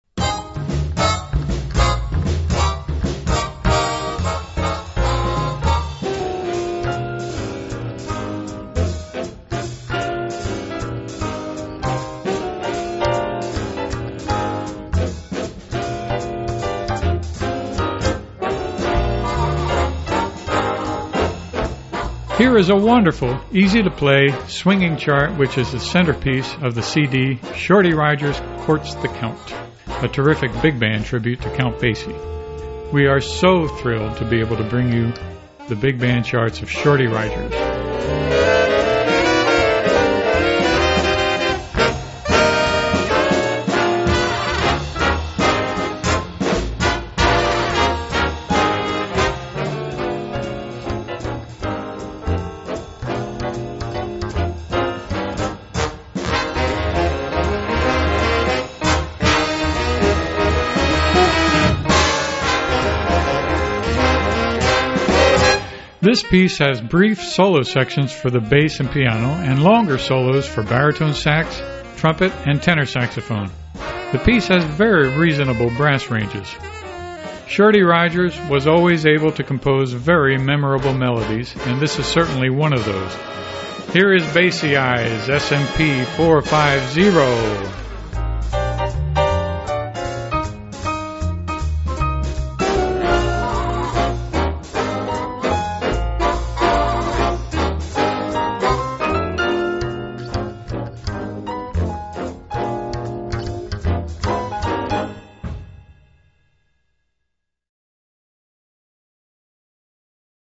BIG BAND
A wonderful, easy, swinging chart